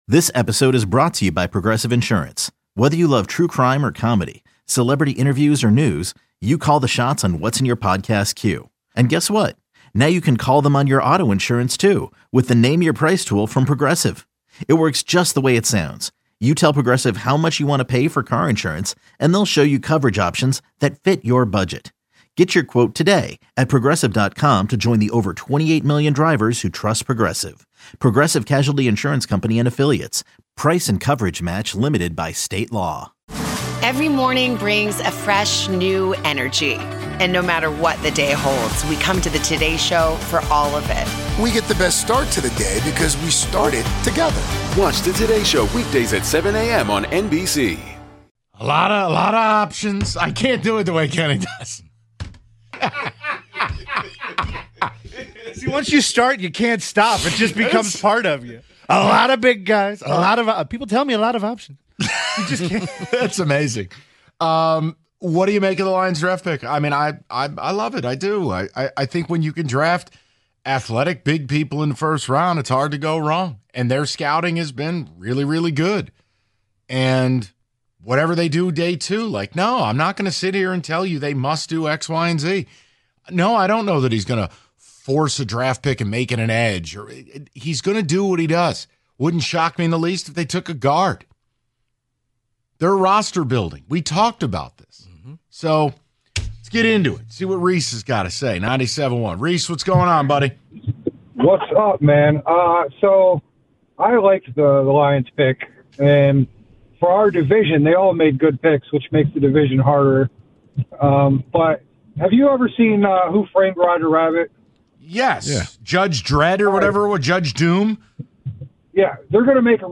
The Callers Chime In On Lions' First-Round Draft Pick